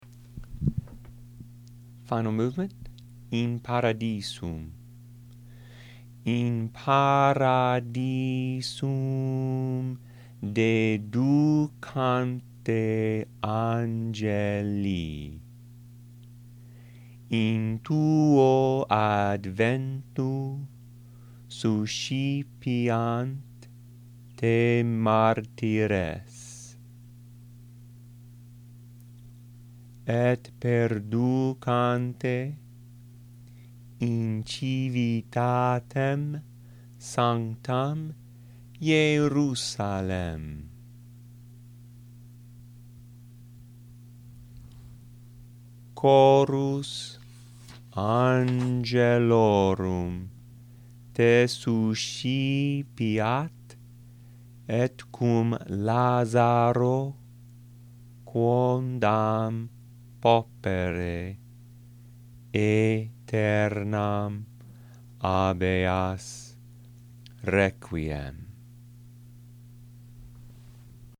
Latin pronunciation